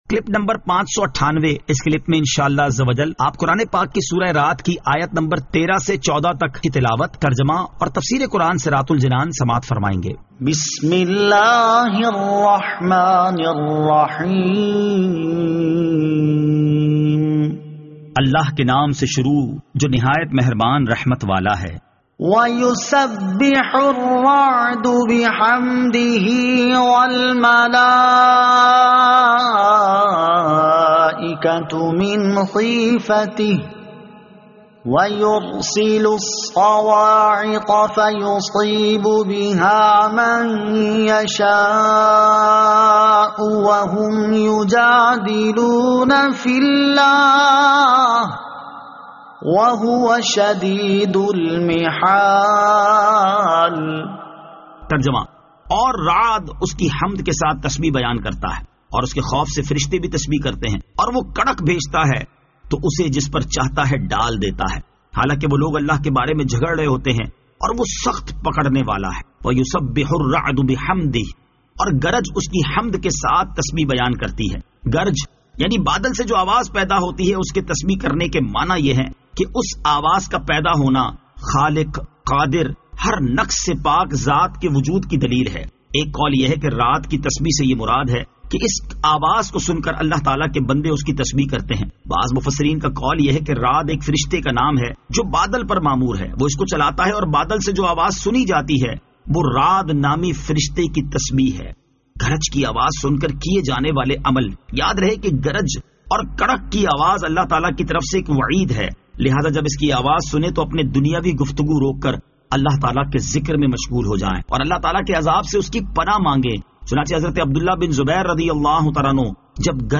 Surah Ar-Rad Ayat 13 To 14 Tilawat , Tarjama , Tafseer